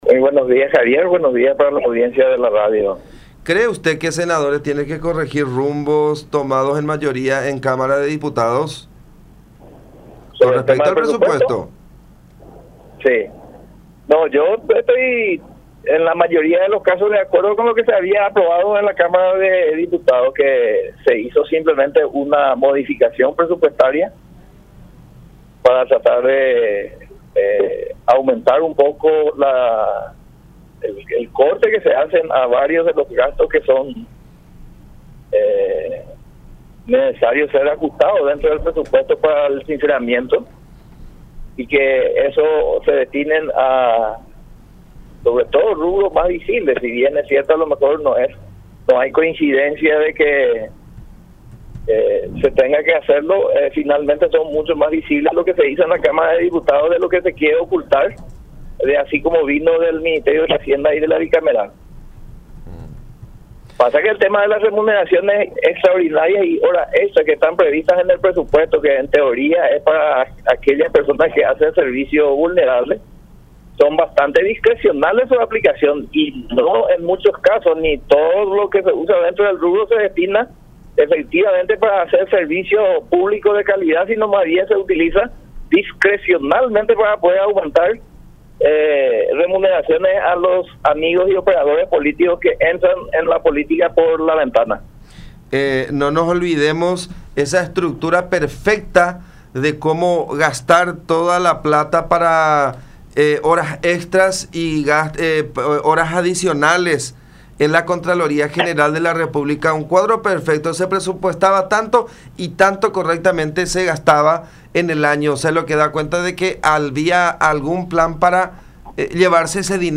Celso Kennedy, diputado PLRA.